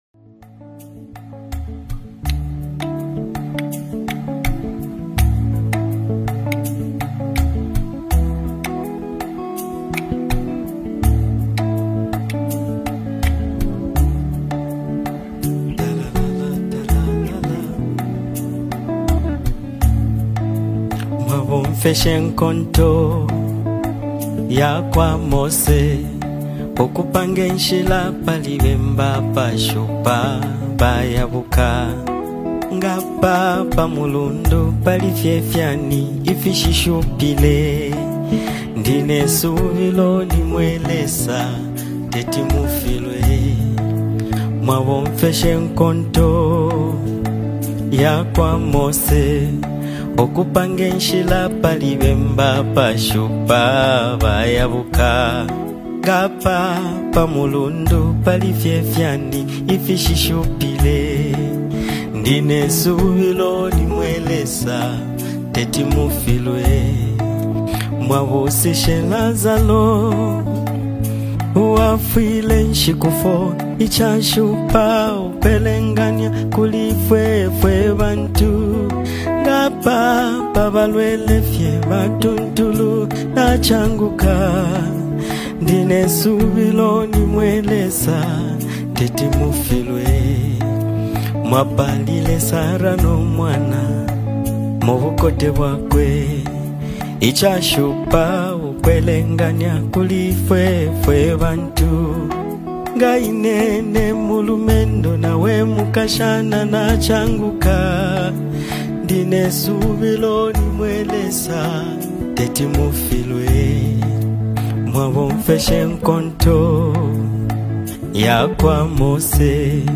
commanding vocals and emotionally driven performance